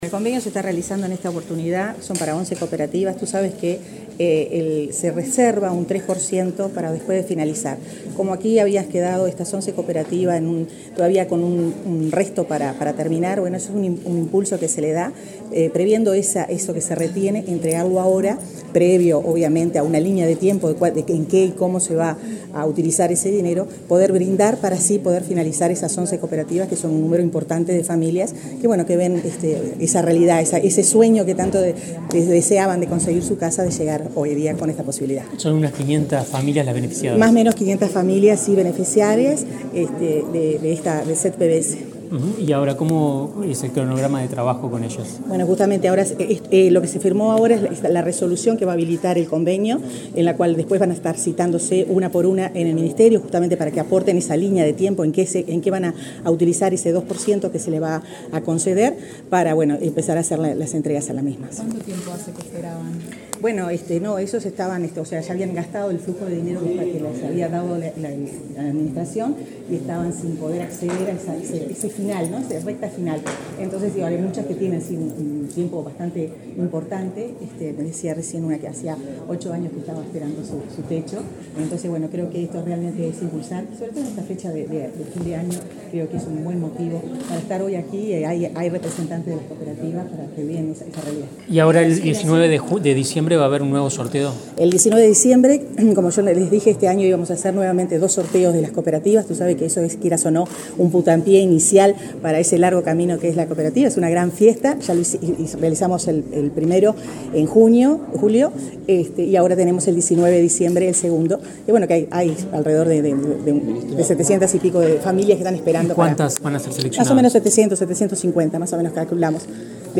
Declaraciones a la prensa de la ministra de Vivienda, Irene Moreira | Presidencia Uruguay
Luego dialogó con la prensa.